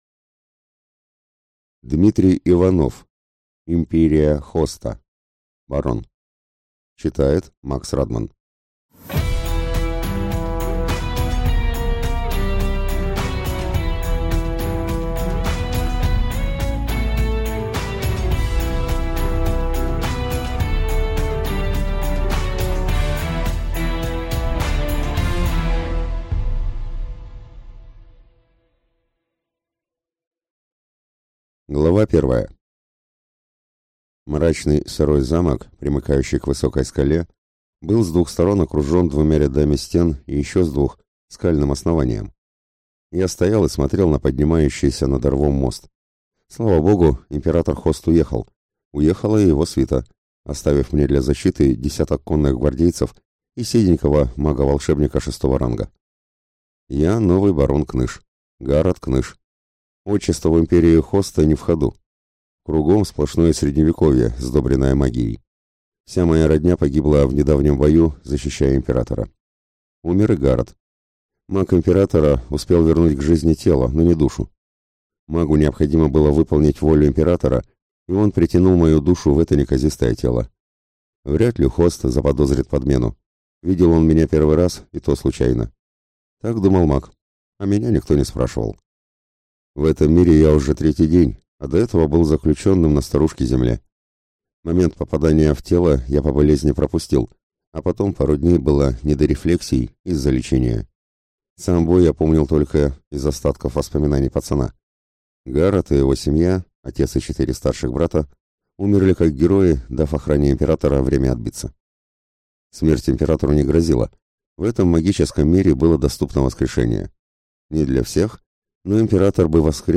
Аудиокнига Империя Хоста. Барон | Библиотека аудиокниг